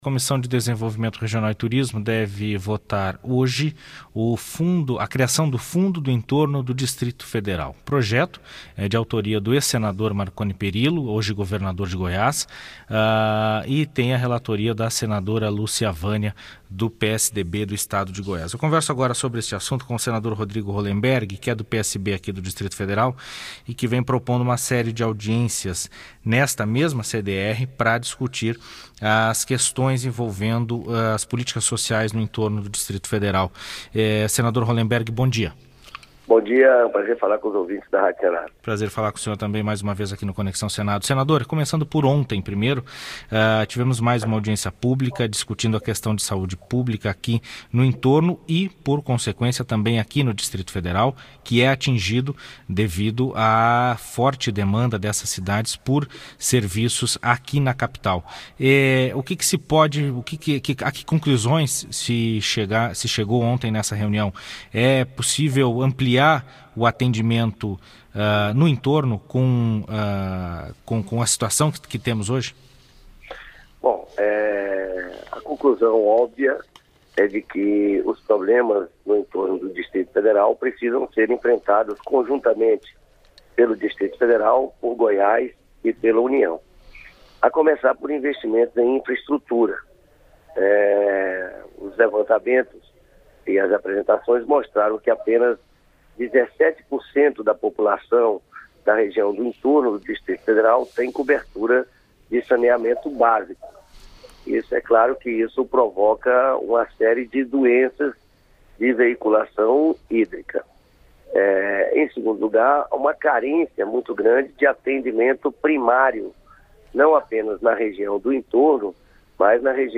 Entrevista com o senador Rodrigo Rollemberg (PSB-DF), presidente da Comissão de Meio Ambiente.